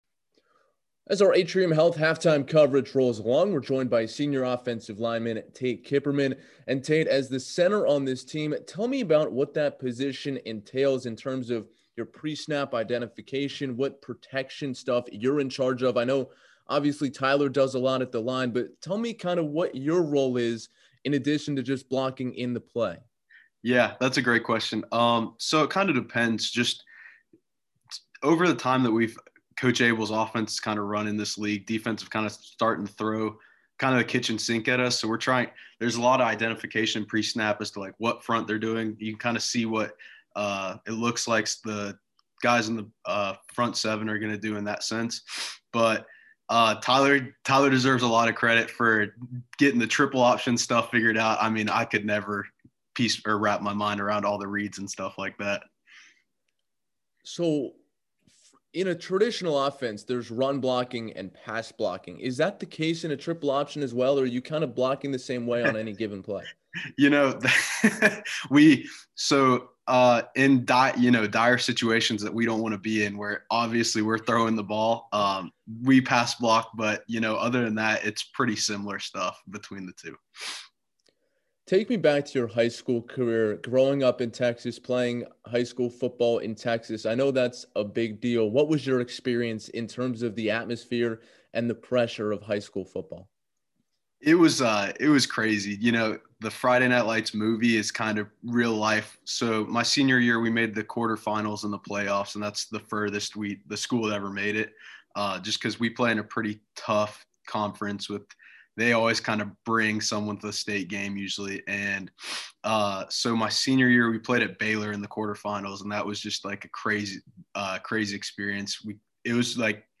Halftime Radio Interview (Pre-recorded)